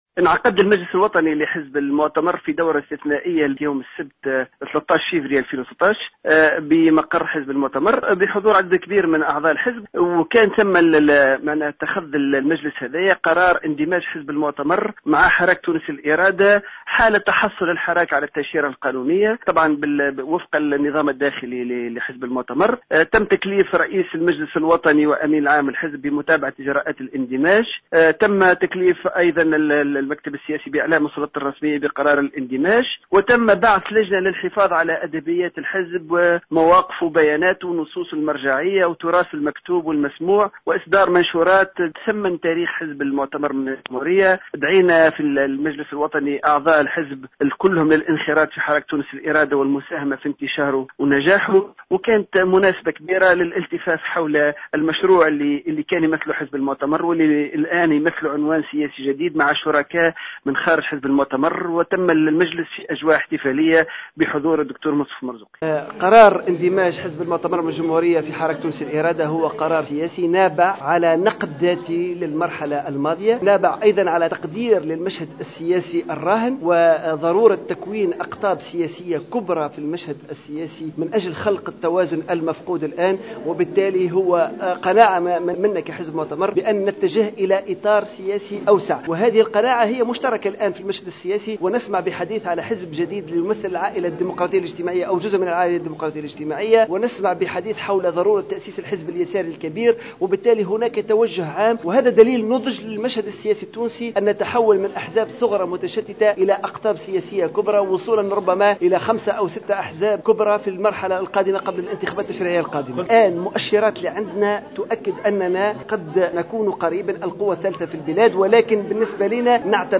قال الأمين العام لحزب المؤتمر من أجل الجمهورية عماد الدايمي في ندوة صحفية...